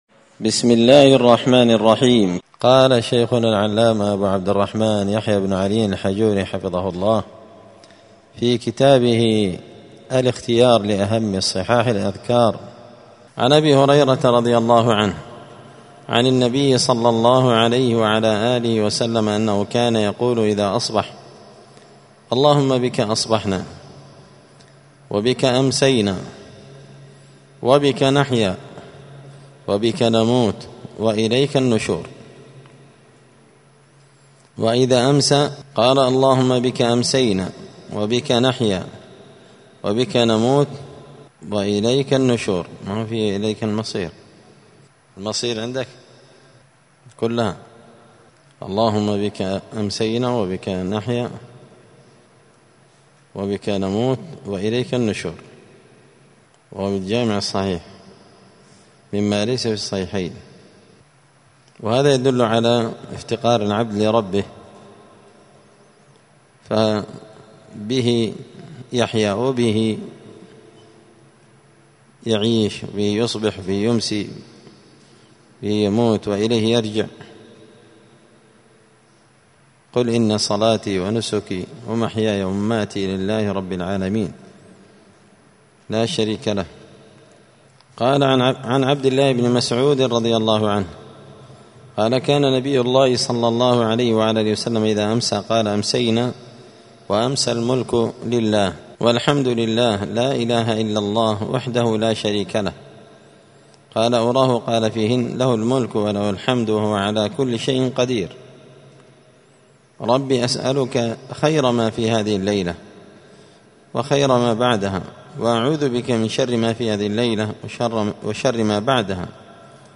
*{الدرس الحادي عشر (11) الحديث الخامس من أذكار الصباح والمساء}*